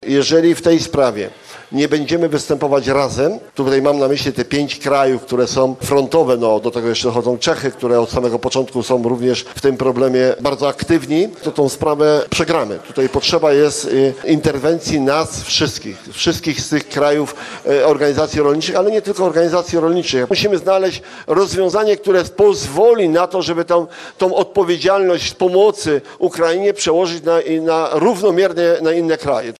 W Rakołupach Dużych na Lubelszczyźnie trwa spotkanie organizacji rolniczych z Polski, Czech, Rumunii, Bułgarii i Słowacji.